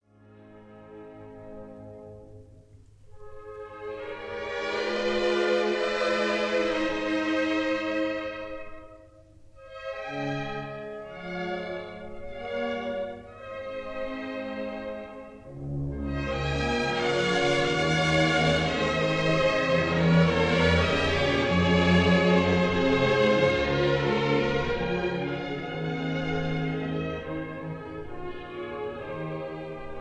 Orchestra
conductor
Recorded in the Kingsway Hall, London on 25 March 1953